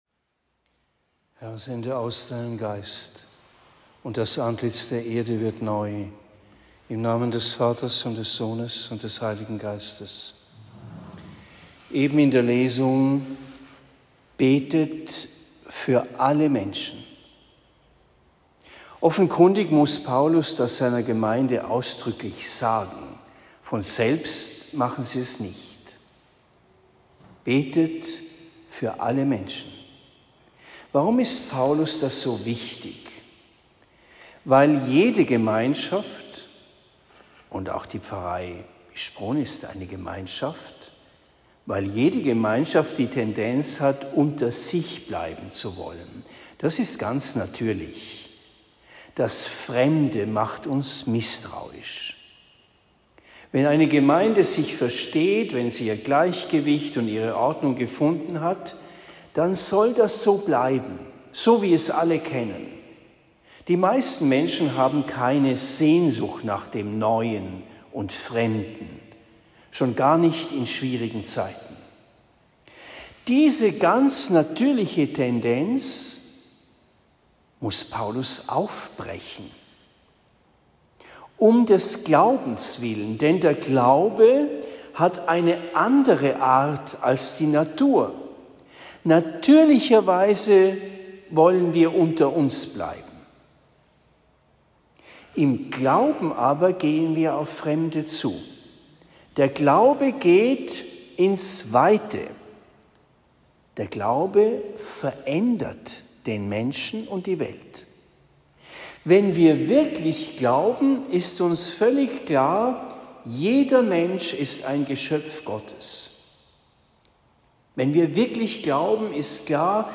Predigt in Bischbrunn am 18. September 2023